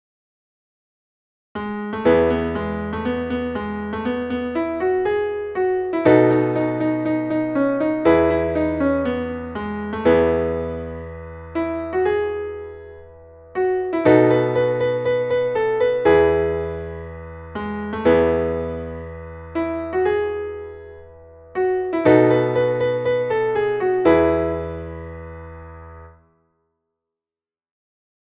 Traditionelles Shanty / Seefahrtslied